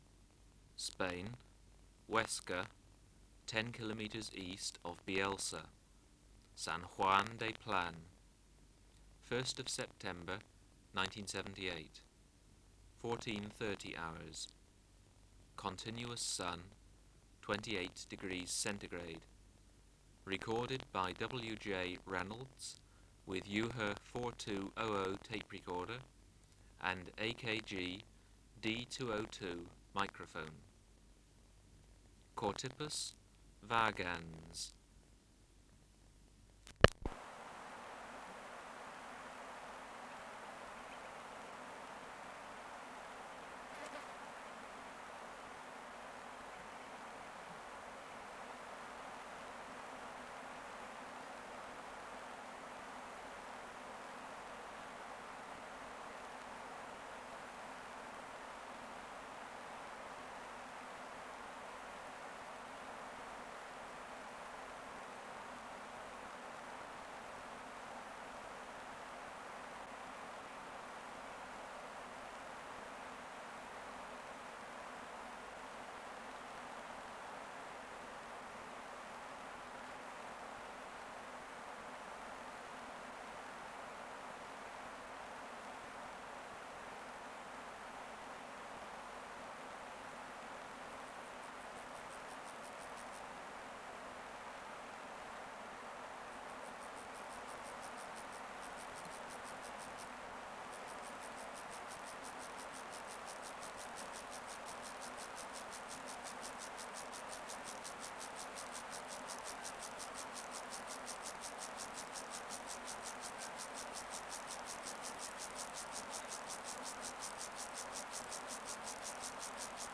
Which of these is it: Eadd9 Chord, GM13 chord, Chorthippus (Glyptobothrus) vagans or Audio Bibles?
Chorthippus (Glyptobothrus) vagans